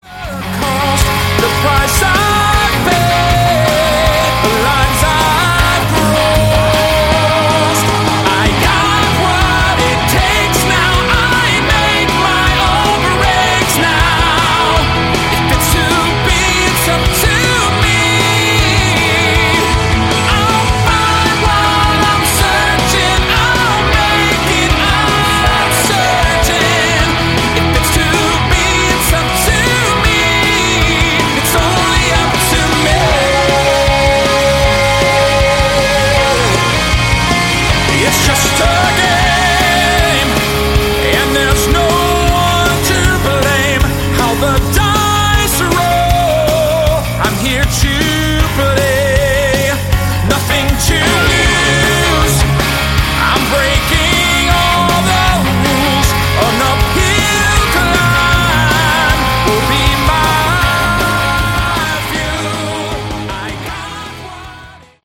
Category: AOR
lead vocals